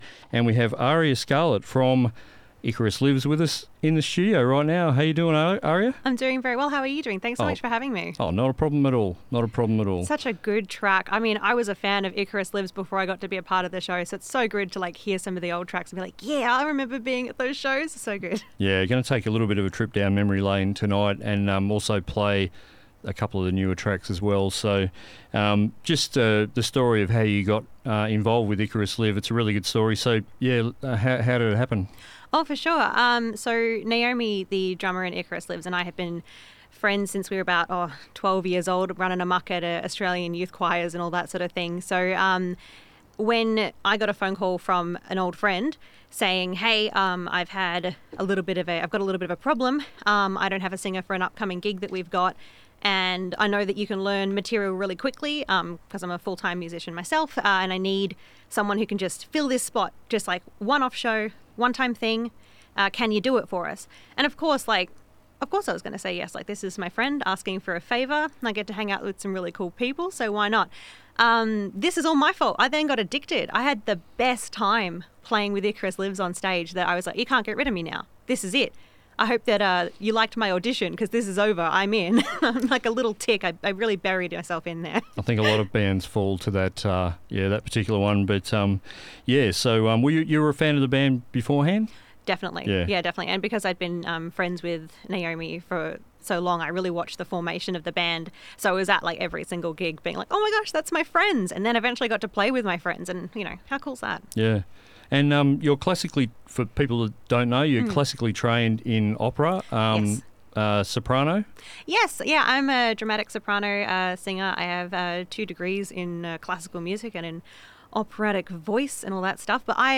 Icaurs-Lives-interview.mp3